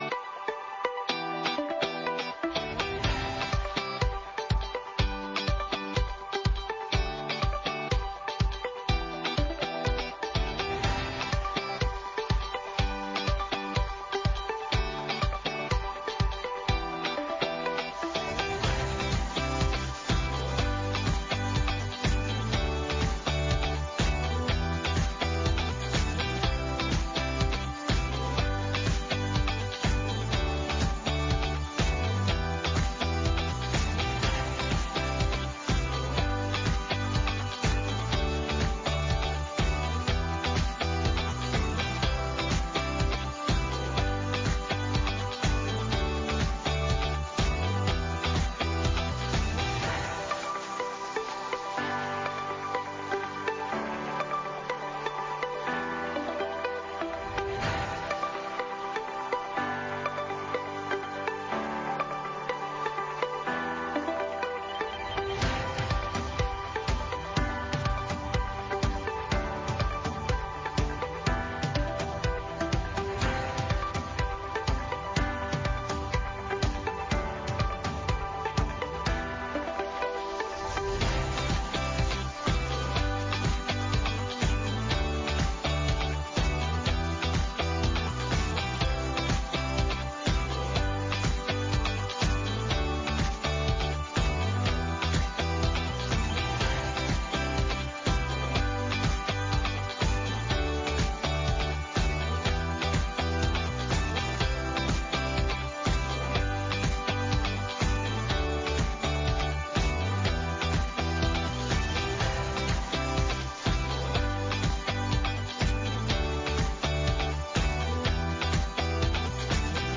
LISTEN (church service)